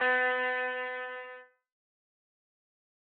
PUPPET Guitar.wav